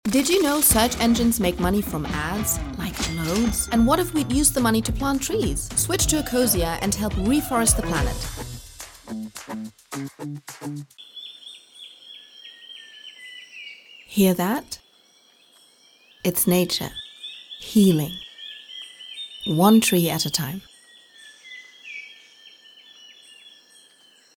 VOICE REEL